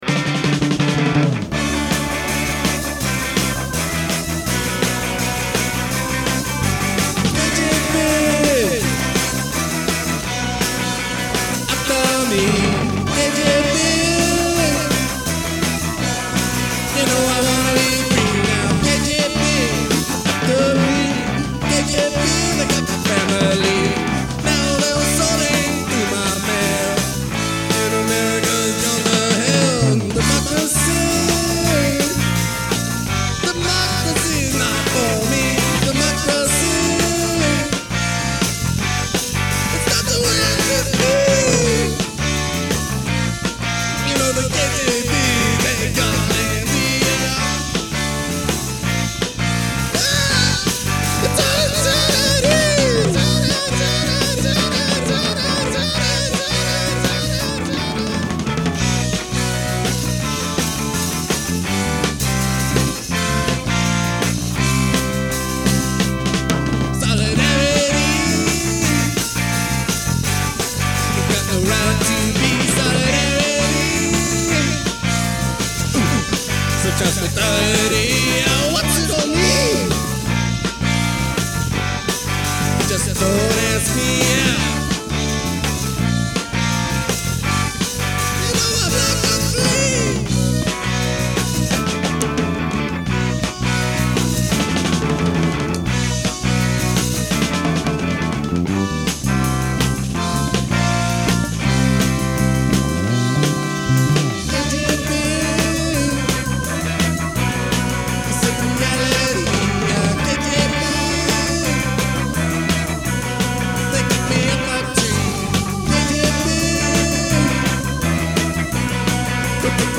percussion
bass guitar
keyboards
guitar, vocals